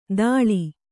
♪ dāḷi